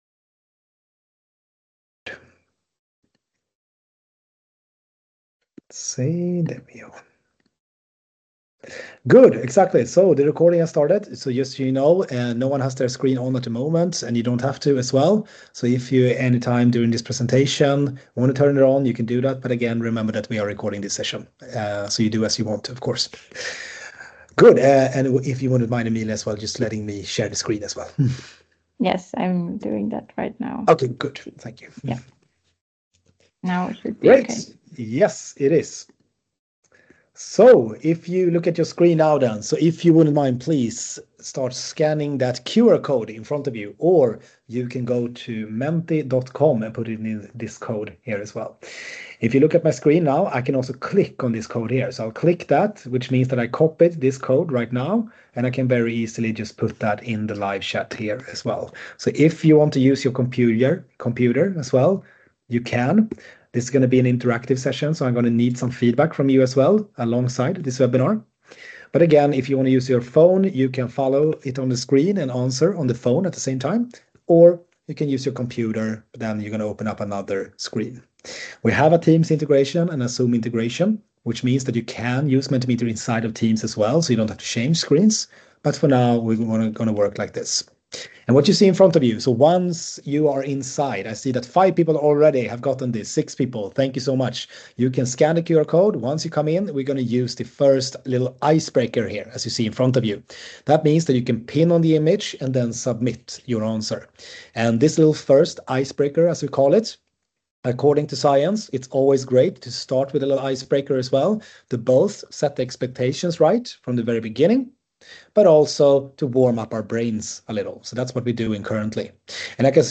Webinar Training with Mentimeter - How to use it, best features and tips & tricks! - 19.9.2024 — Moniviestin